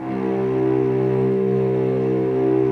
Index of /90_sSampleCDs/Roland LCDP13 String Sections/STR_Vcs II/STR_Vcs6 p Amb